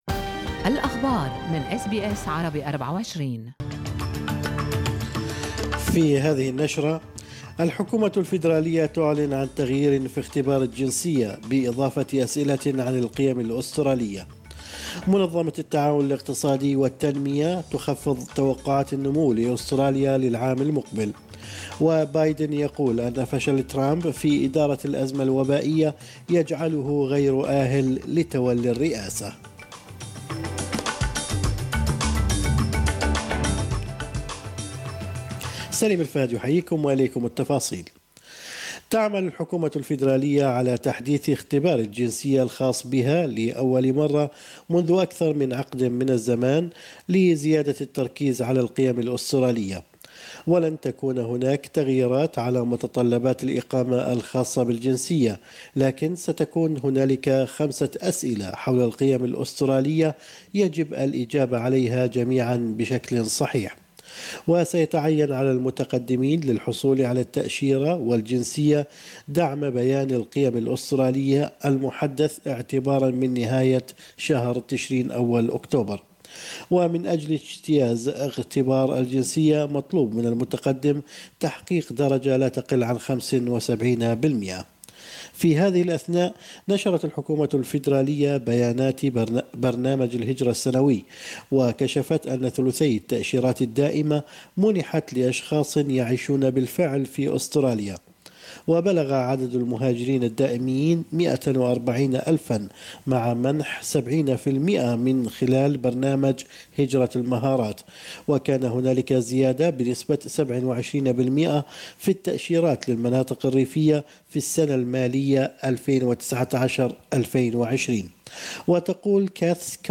نشرة اخبار الصباح 17/9/2020